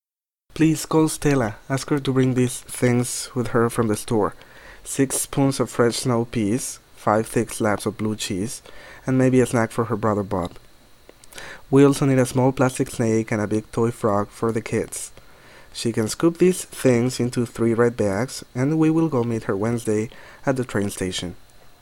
A Mexican Accent
El orador es nativo de la ciudad de Mexico DF, Mexico, y se observa un moderado acento en su pronunciación.
MEXICO.mp3